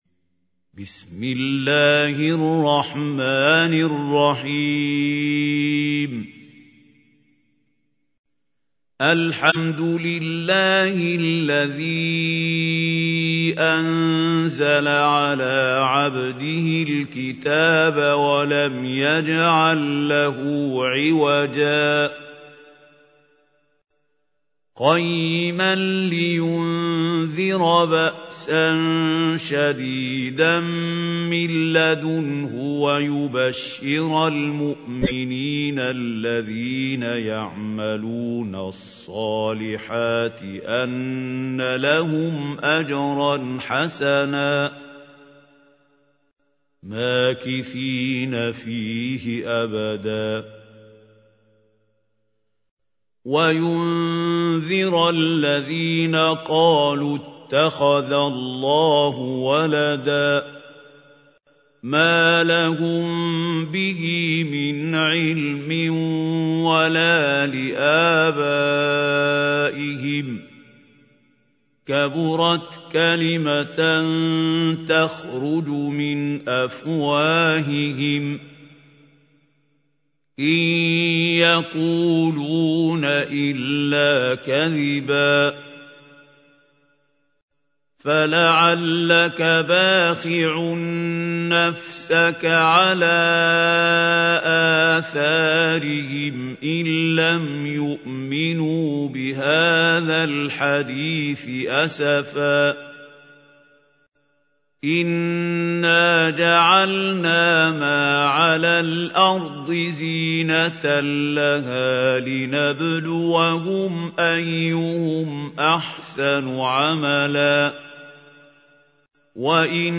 تحميل : 18. سورة الكهف / القارئ محمود خليل الحصري / القرآن الكريم / موقع يا حسين